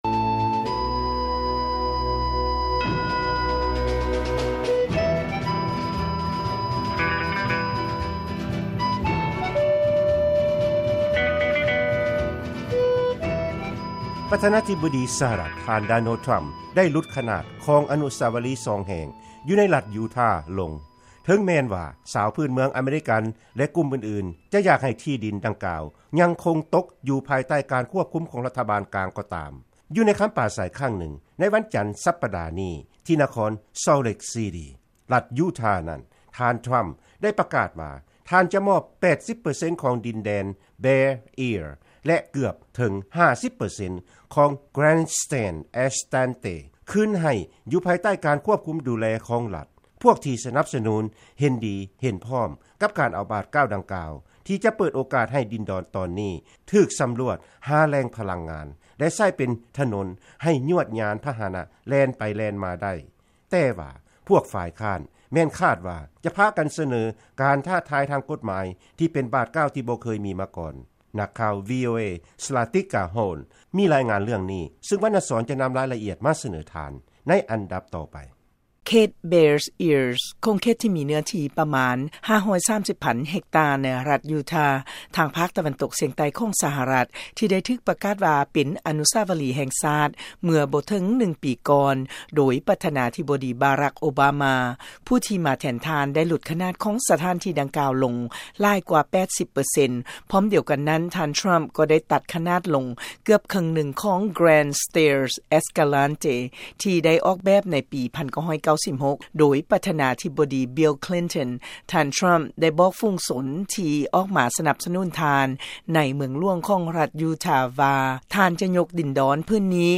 ລາຍງານກ່ຽວກັບການຫຼຸດຂະໜາດຂອງອະນຸສາວະລີແຫ່ງຊາດລົງ